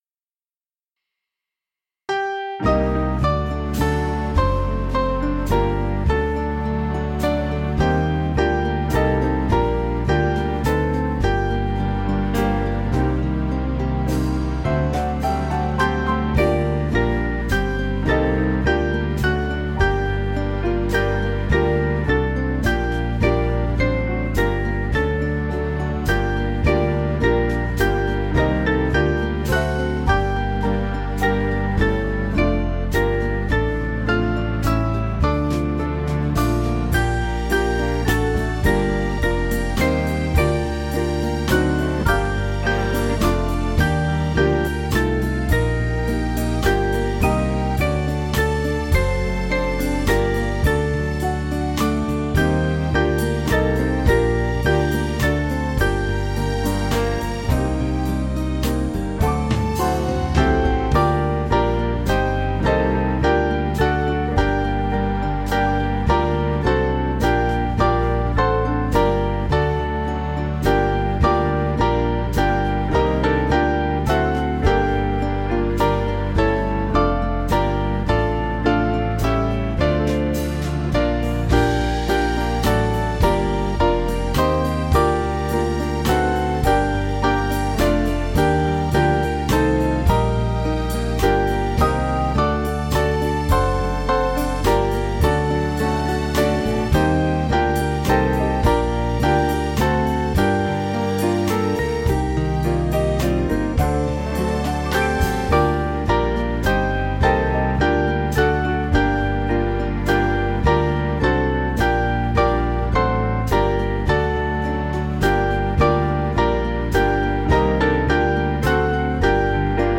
Small Band
(CM)   3/Bb 478.6kb